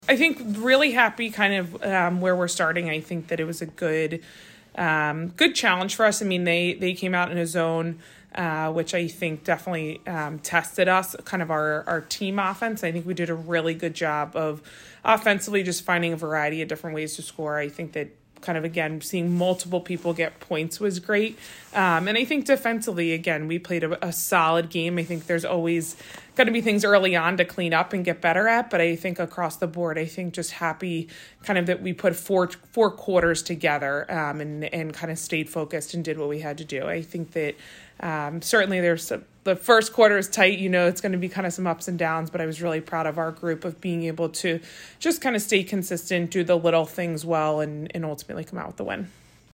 Merrimack Postgame Interview